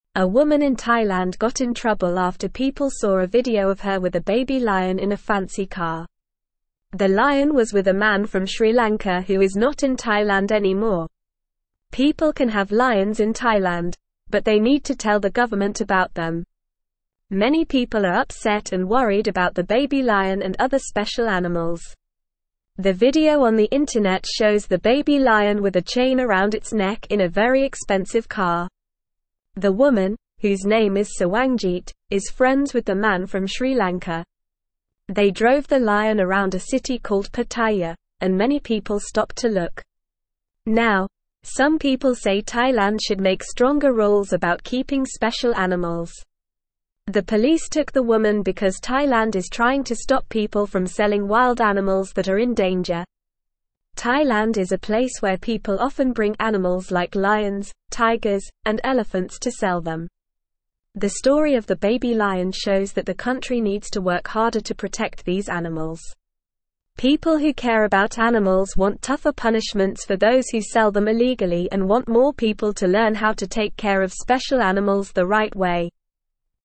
Normal
English-Newsroom-Lower-Intermediate-NORMAL-Reading-Trouble-for-Woman-Who-Let-Baby-Lion-Ride-in-Car.mp3